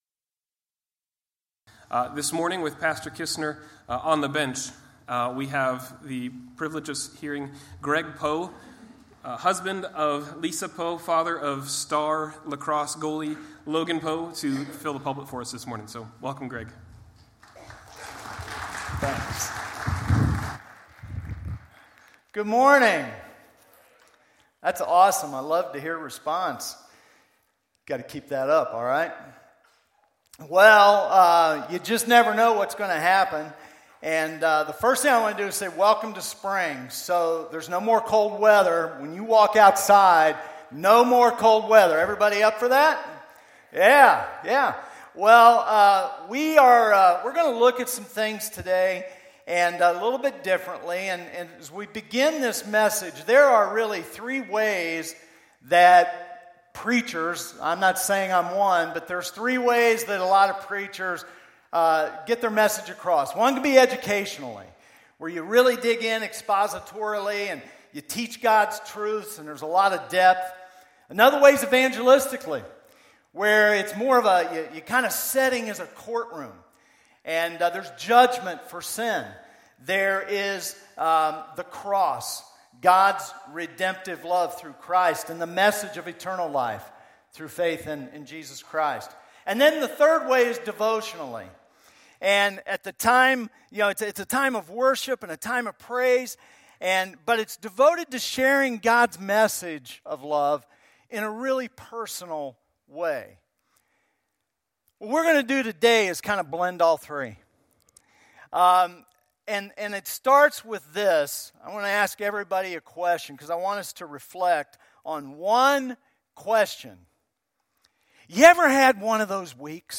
2016 Categories Sunday Morning Message Download Audio A Look at the Week Preceding the Crucifixion